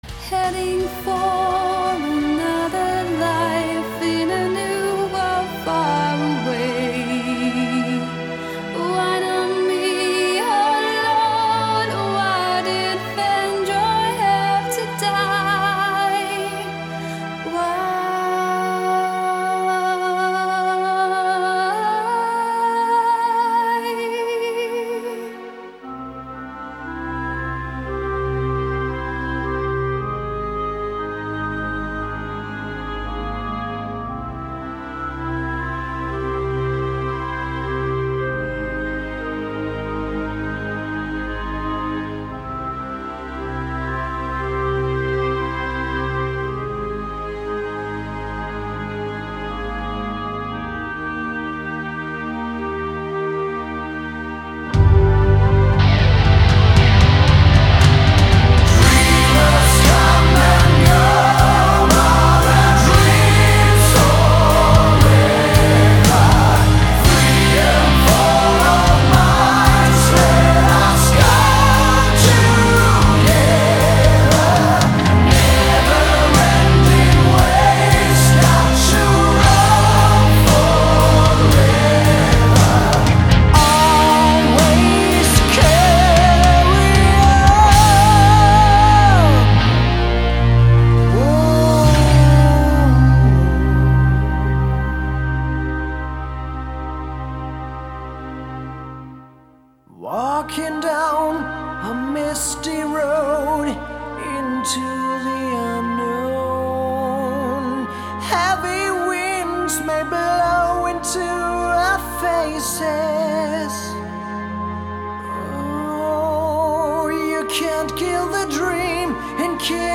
Genre: Power Metal